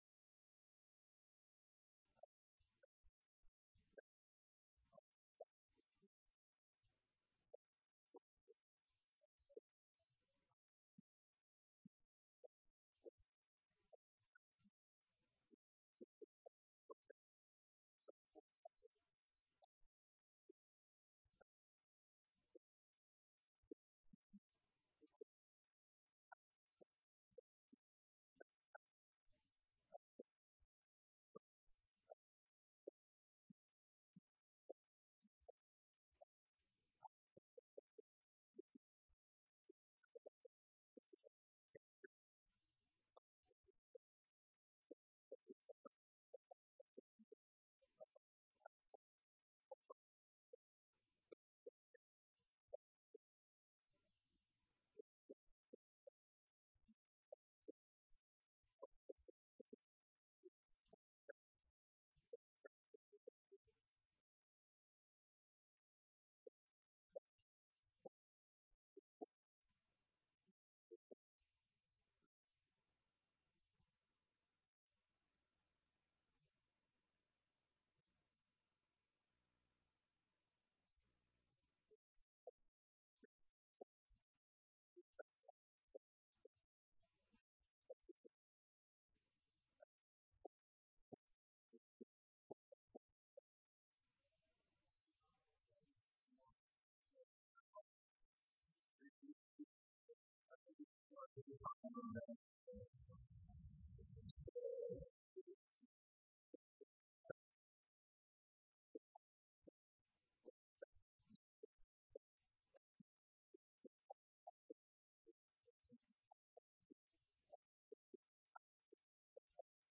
Event: 2019 Men's Development Conference
lecture